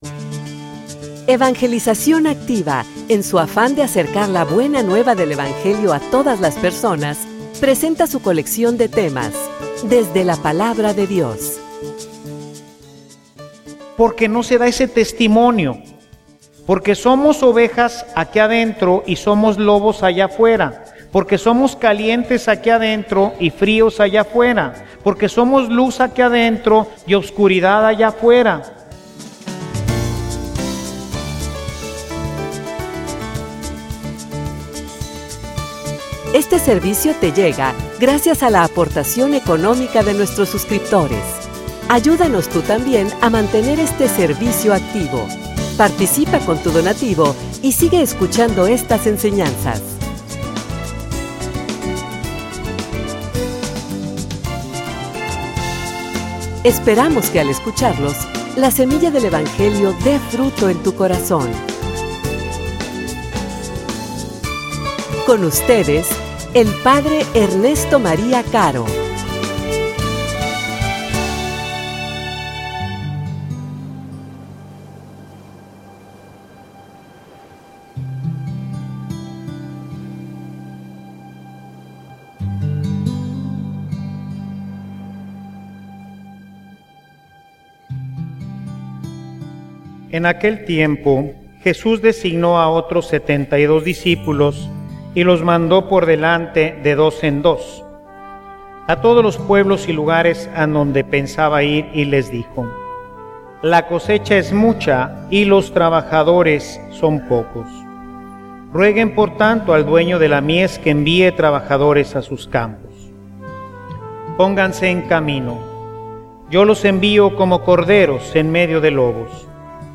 homilia_Una_mision_dificil_y_peligrosa.mp3